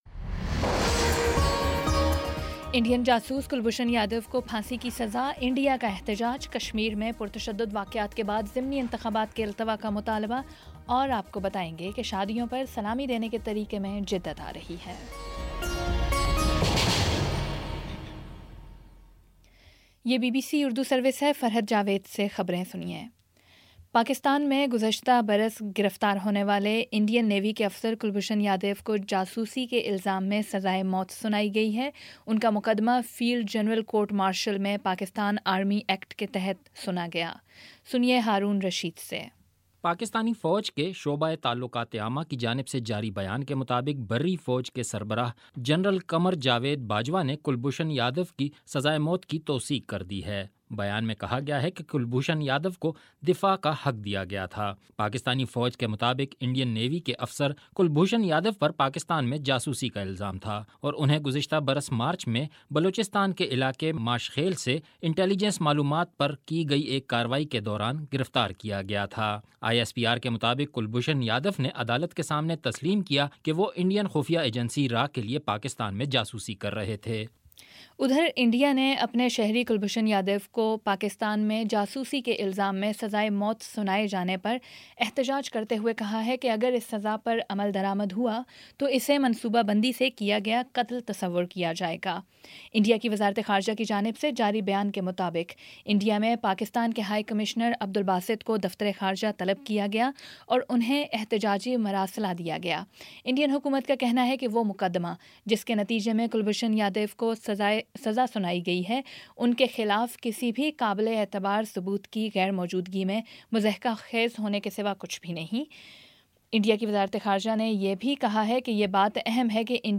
اپریل 10 : شام چھ بجے کا نیوز بُلیٹن